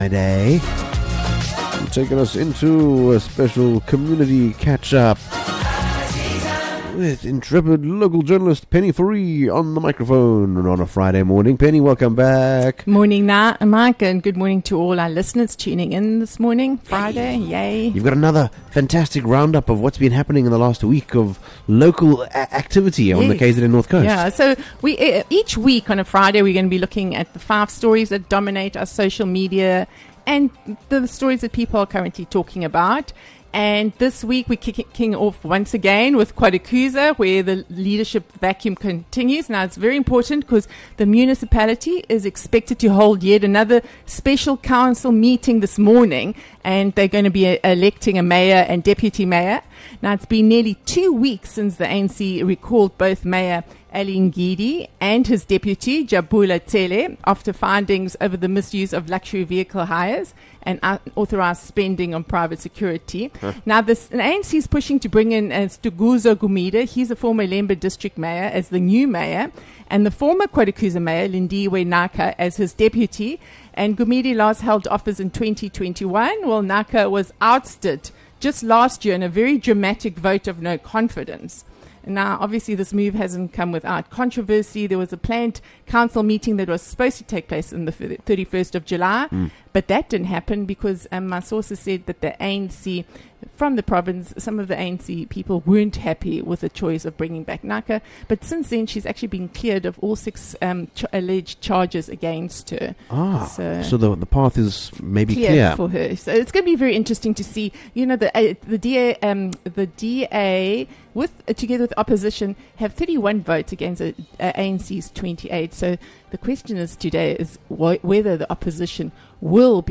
From the air we breathe to the power that keeps our lights on, and even the fight to free Opal the orangutan. These are the five stories dominating the North Coast this week, all in under ten minutes. Don’t miss our weekly news segment every Friday on 88FM